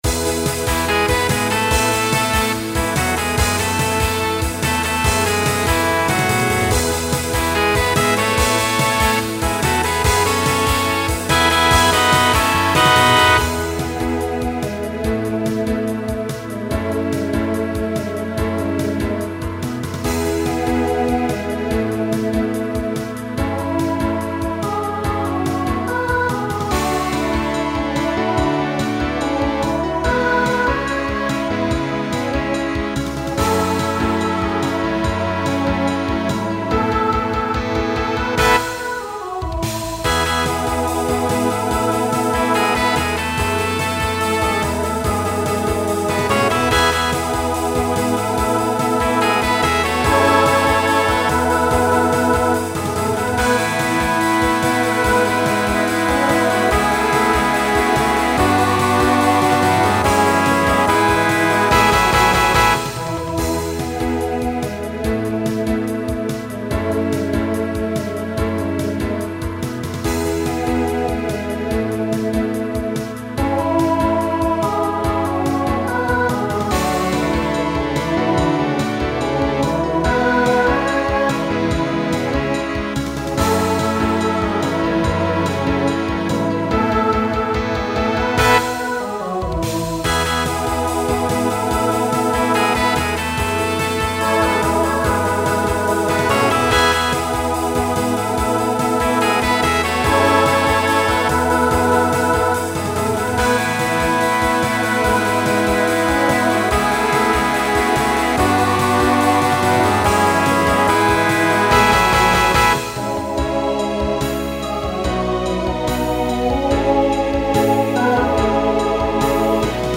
Voicing SATB Instrumental combo Genre Country , Pop/Dance